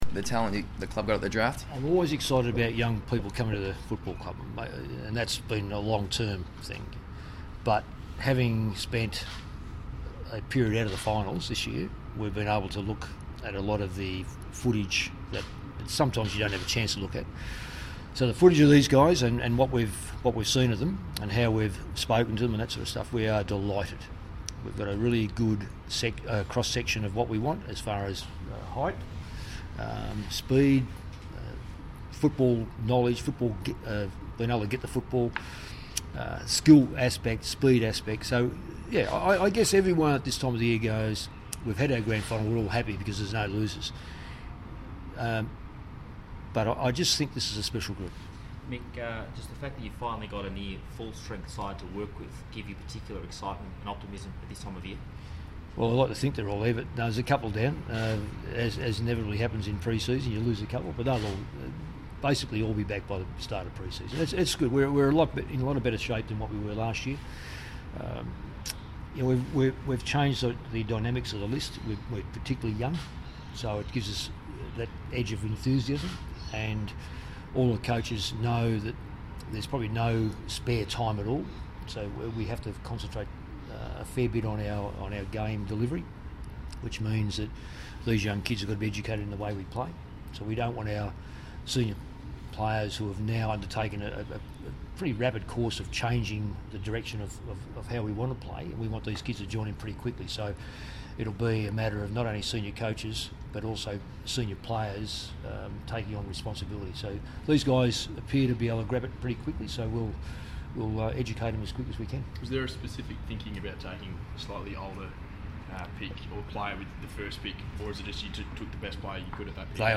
Coach Mick Malthouse says Carlton's new recruits will provide a good cross section of what the club needs. (Audio courtesy of 3AW Melbourne)